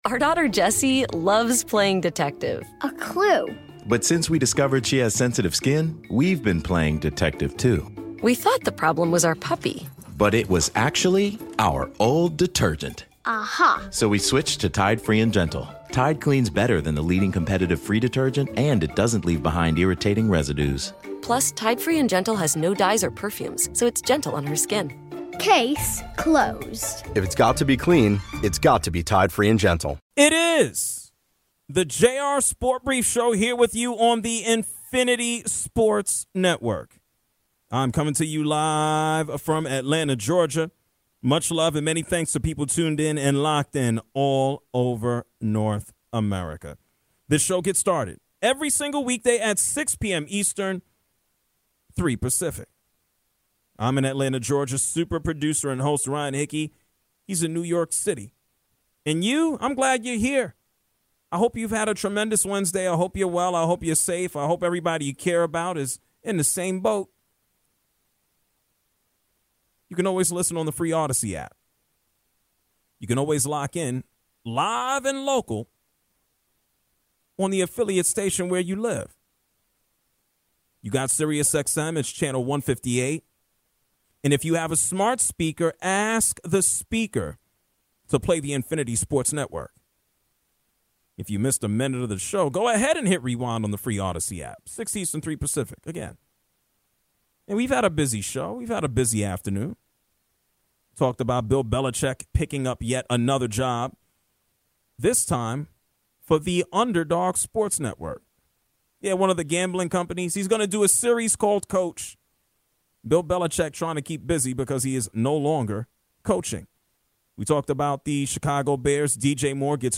Top 6 most suffering fan bases l #1 reveal l Calls on the fan bases that have been the most tortured